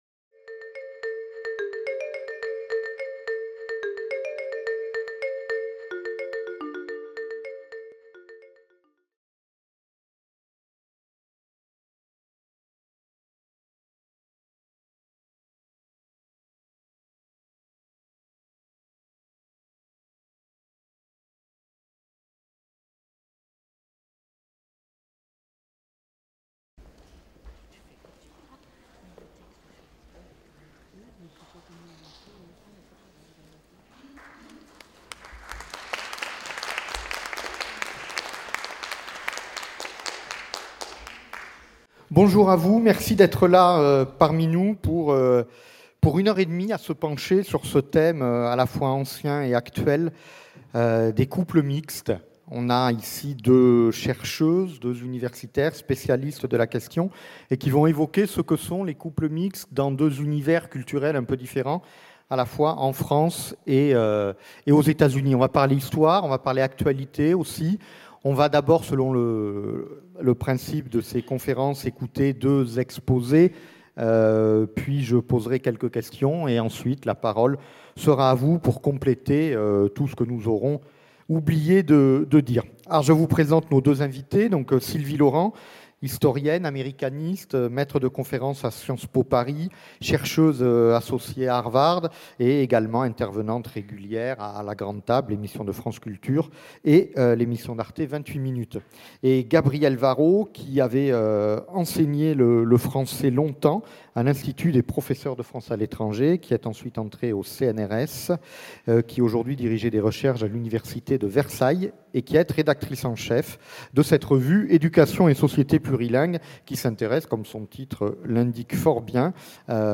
Conférence de l’Université populaire du quai Branly (UPQB), donnée le 23 mars 2016